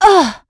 Valance-Vox_Damage_01.wav